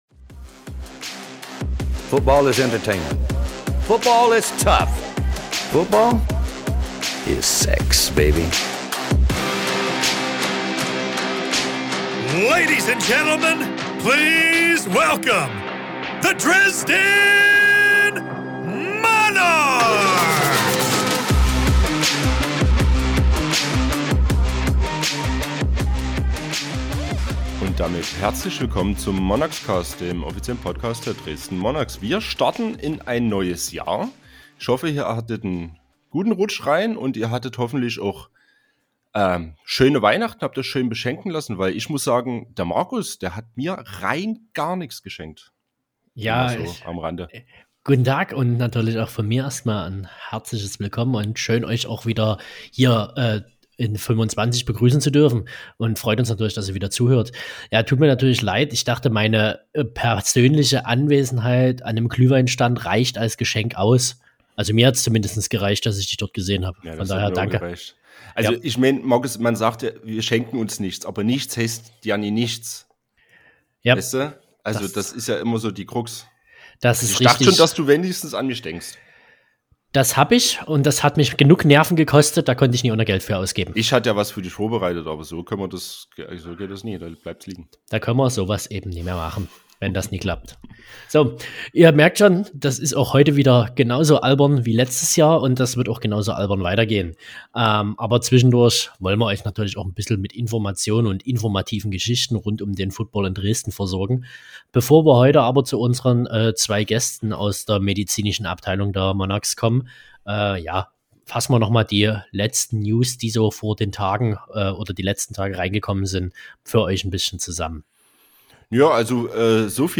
Offseason-Zeit ist Interview-Zeit.